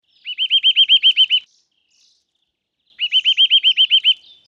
Der Kleiber